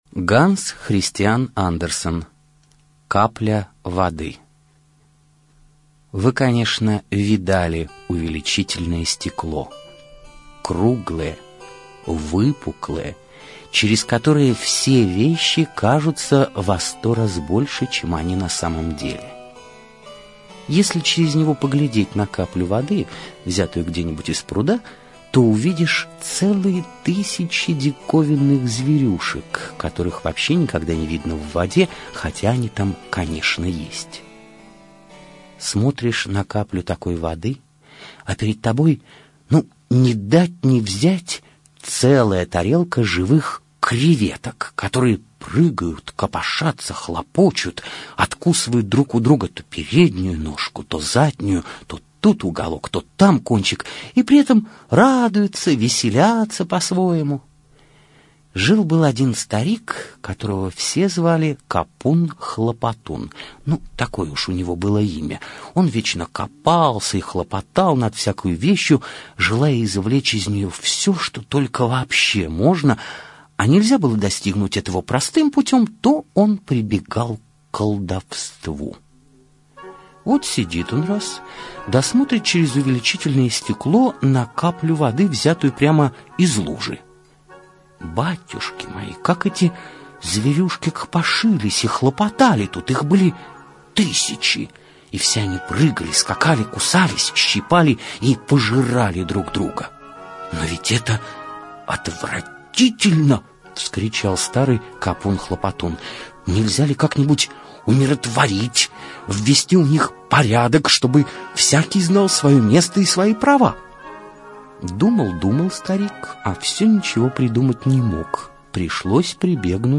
Капля воды - аудиосказка Андерсена.